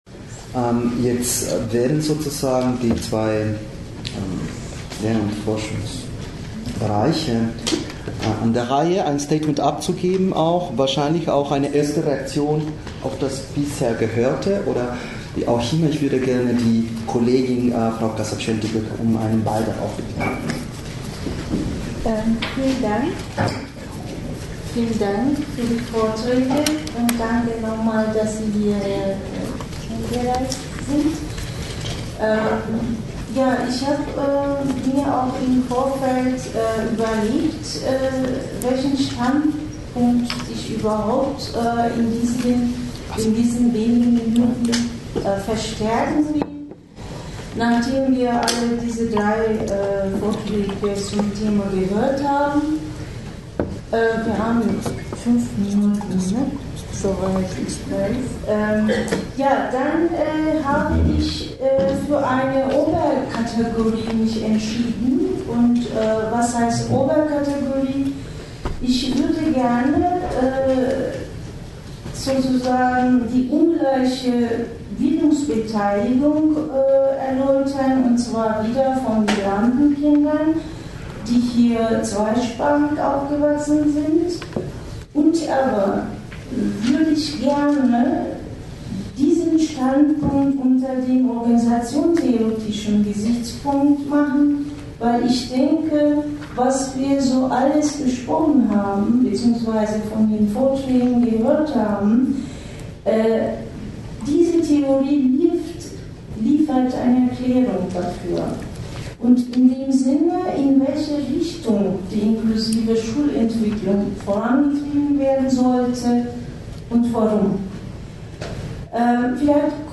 Die drei ReferentInnen werden bei dieser Veranstaltung ihre wissenschaftliche Expertise zu Fragen der Bildungssituation von Kindern und Jugendlichen mit Behinderungen sowie von Kindern und Jugendlichen mit Migrationsgeschichte einbringen und mit dem Publikum disku- tieren.